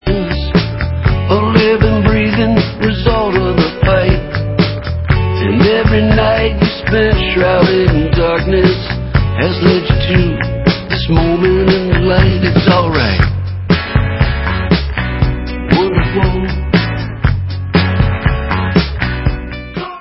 americká indie-rocková kapela